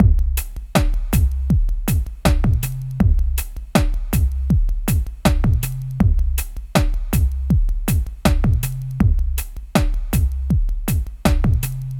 Downtempo 26.wav